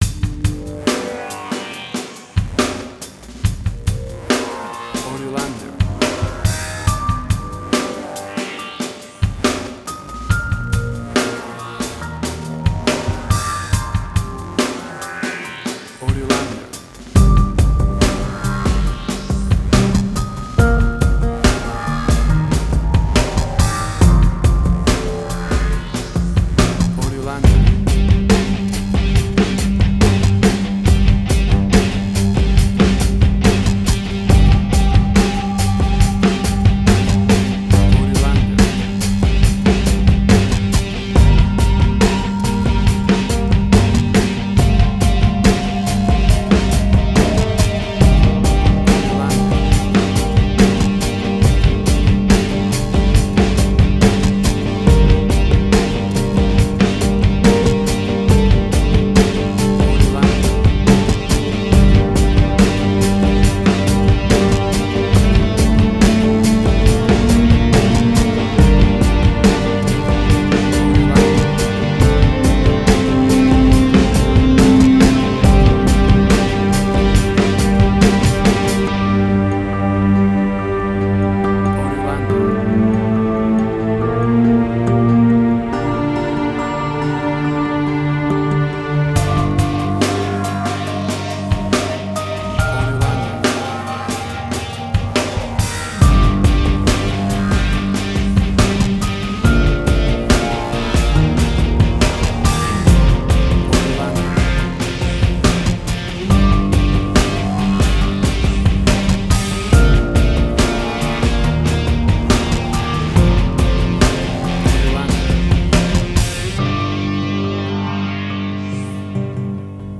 Suspense, Drama, Quirky, Emotional.
Tempo (BPM): 70